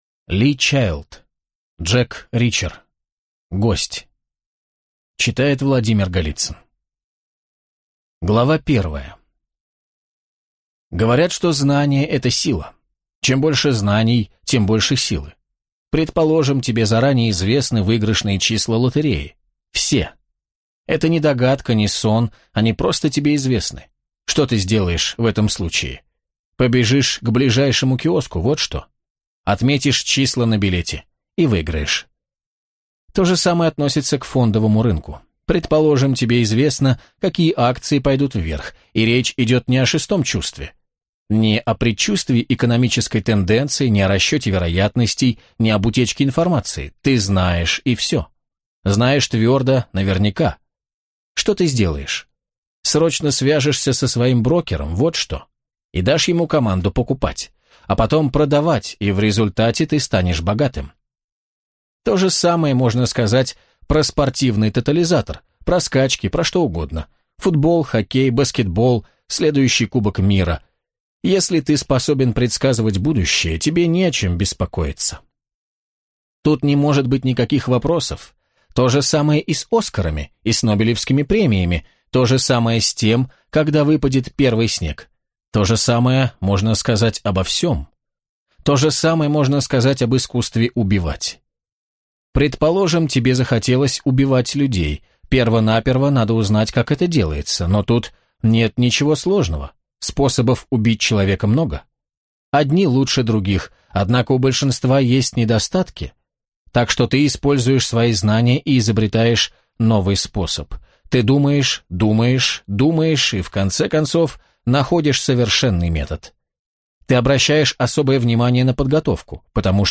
Аудиокнига Джек Ричер: Гость | Библиотека аудиокниг